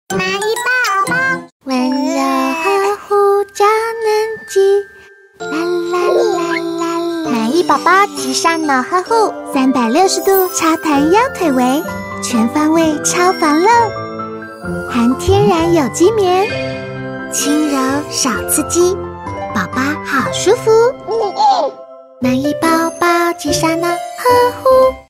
國語配音 女性配音員